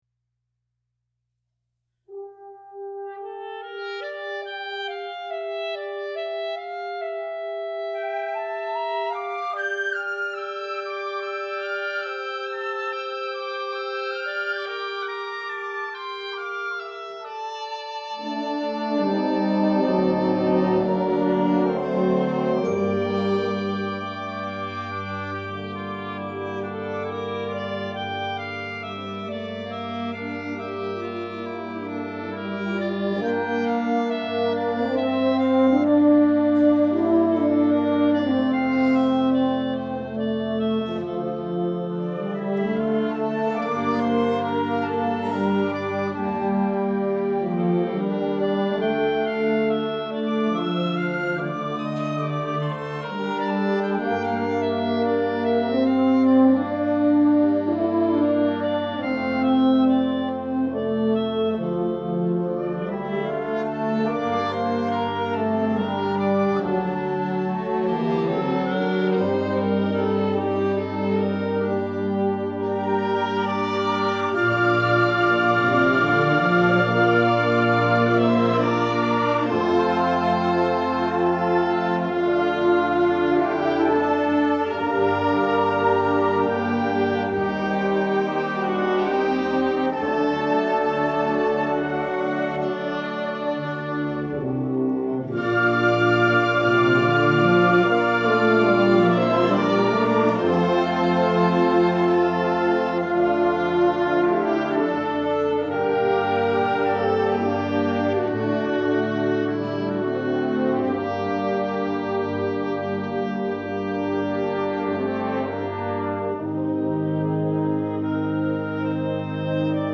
Winter Concert
--SYMPHONIC BAND--